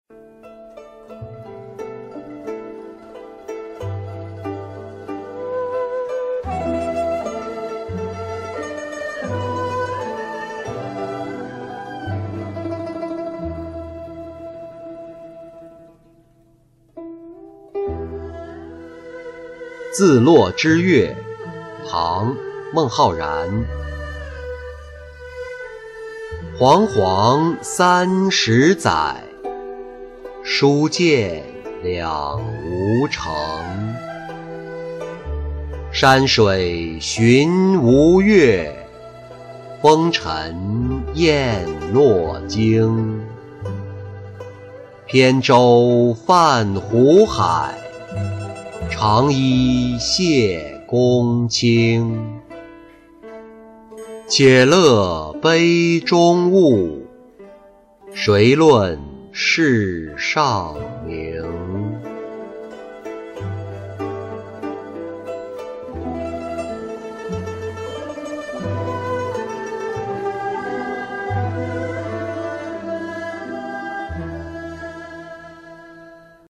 自洛之越-音频朗读